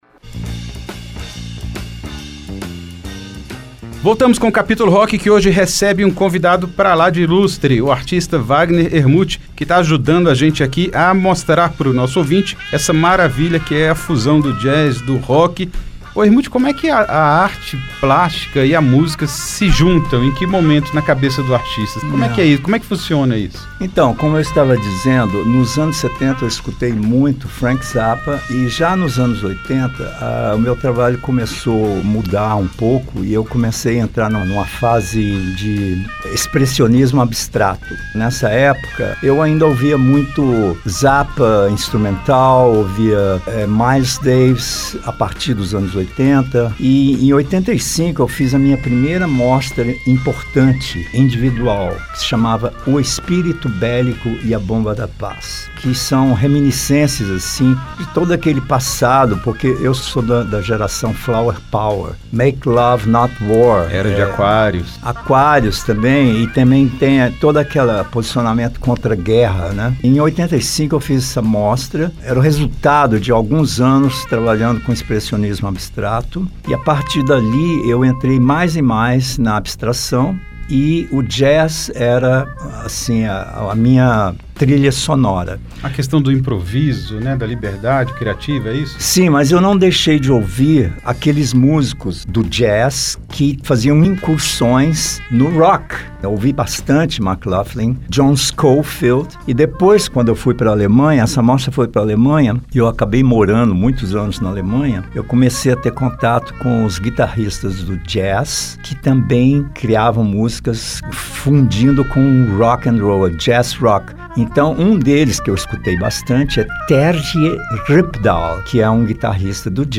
Rock fusion Jazz